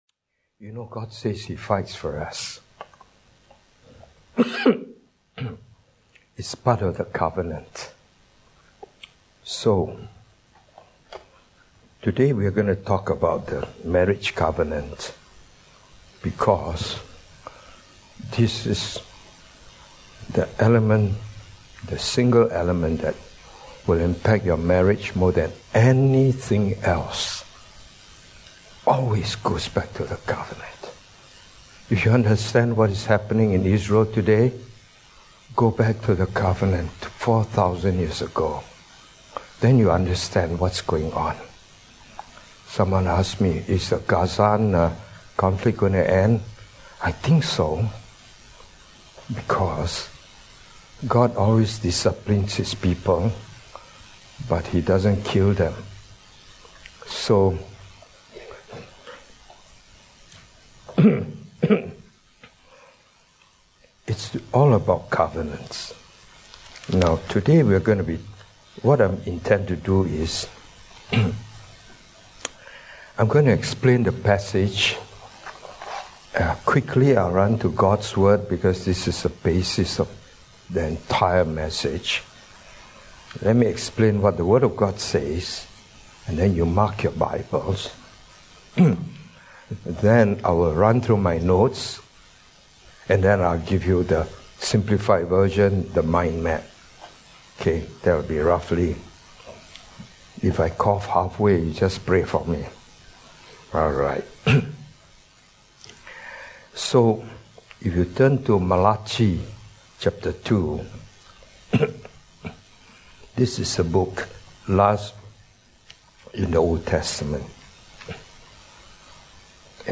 Otherwise just click, the video / audio sermon will just stream right to you without download.